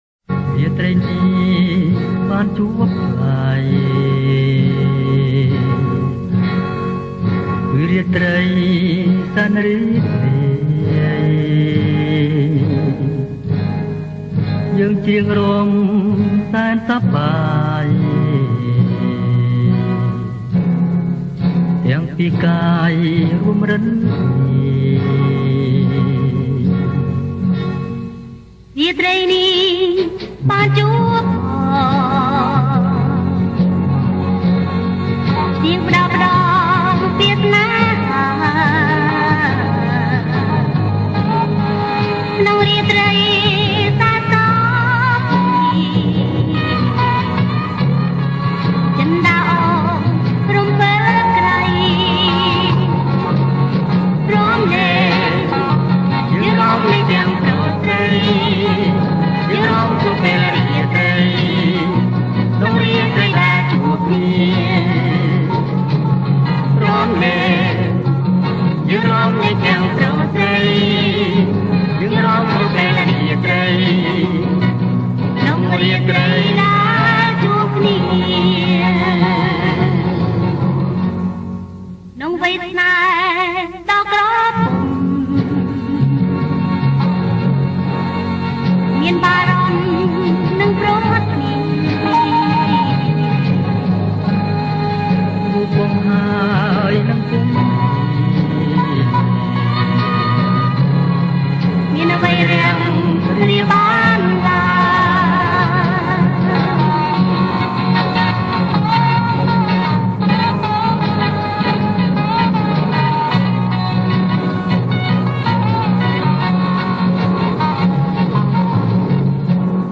ប្រគំជាចង្វាក់ Slow+Jerk